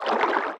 Sfx_creature_penguin_idlesea_A_03.ogg